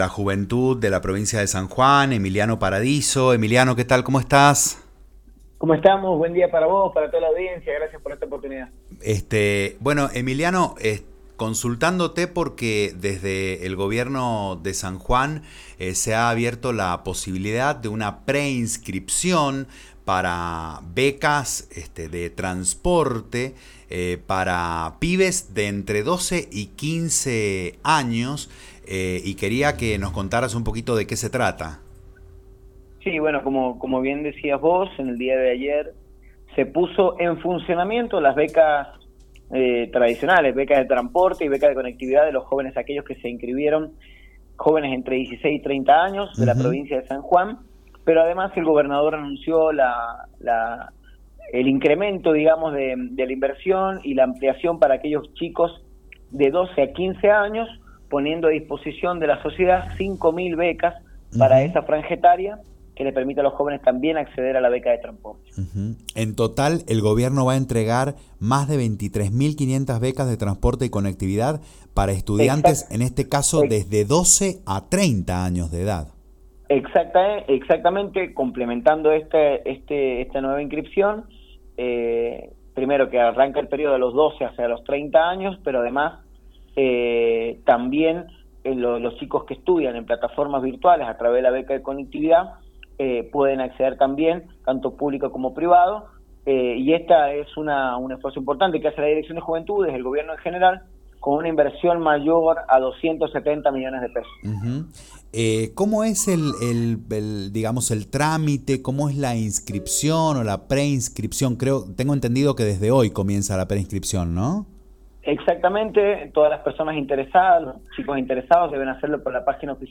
Escuchá la nota completa de Ni Los Chocos con el Director de Juventudes Emiliano Paradiso, acá: